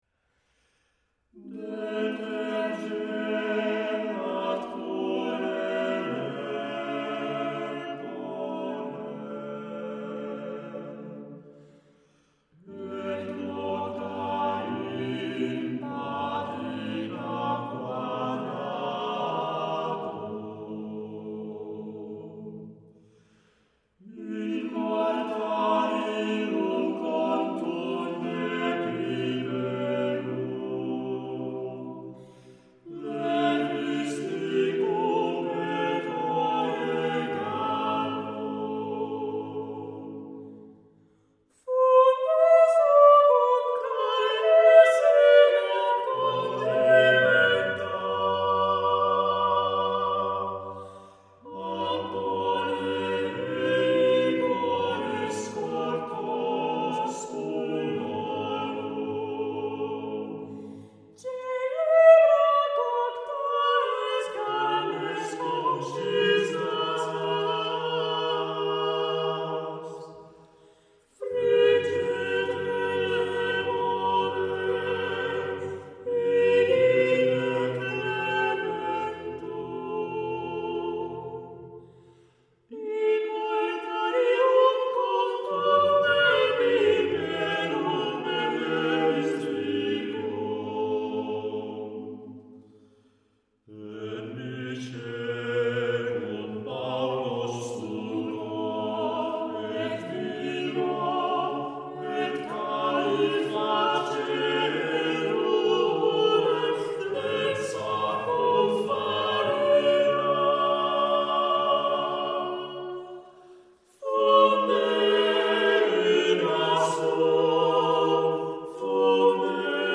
has provided us with two more hymns